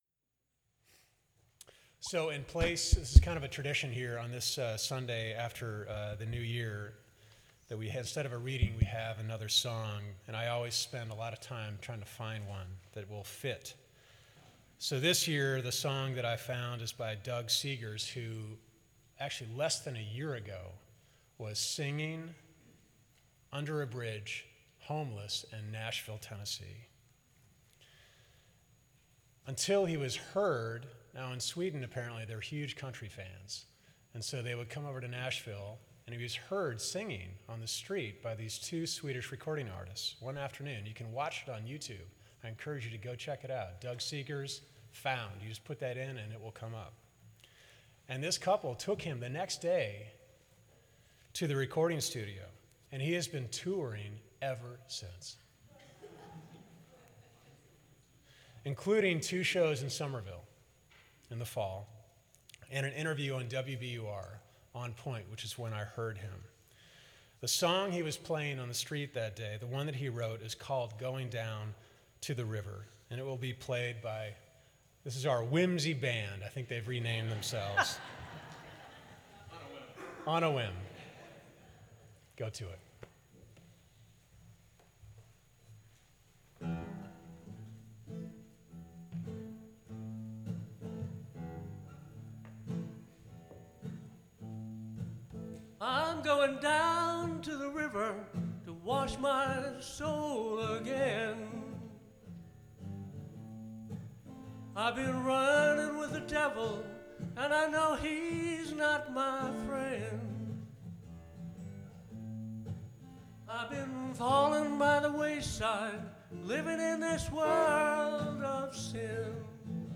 Sermon1_4_15.mp3